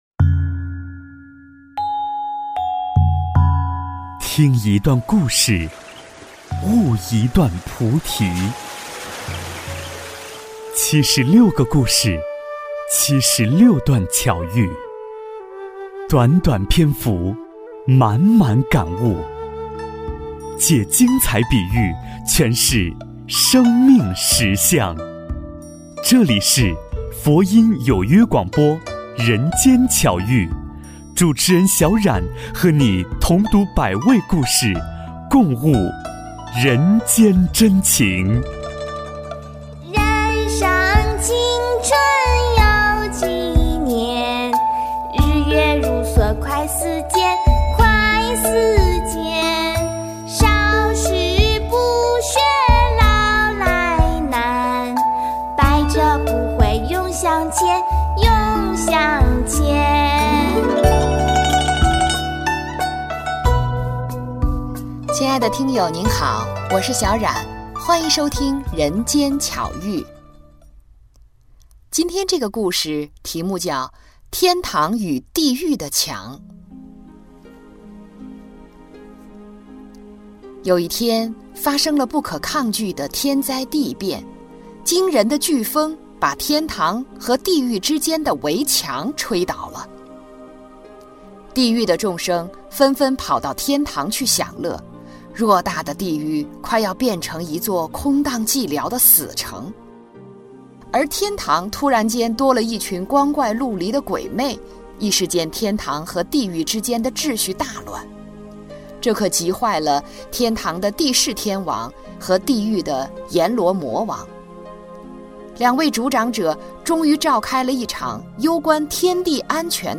天堂与地狱的墙--有声佛书